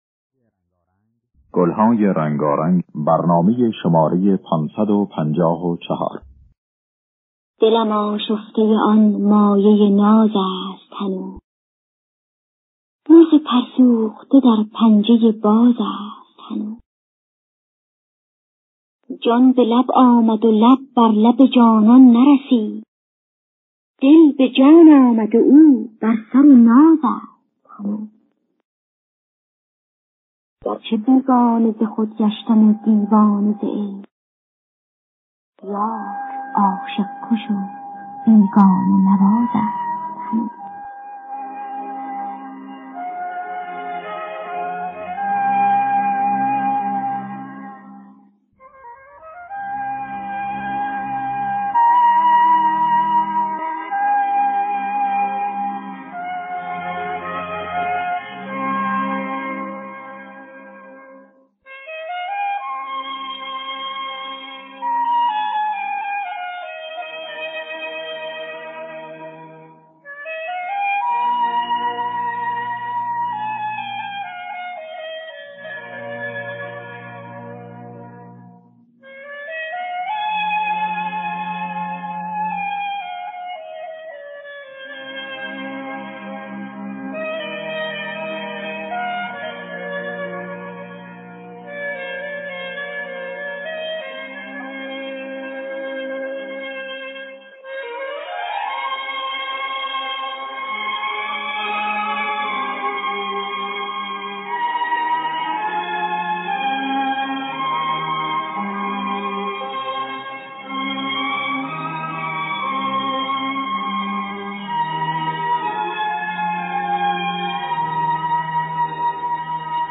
دانلود گلهای رنگارنگ ۵۵۴ با صدای محمودی خوانساری، سیما بینا در دستگاه دشتی.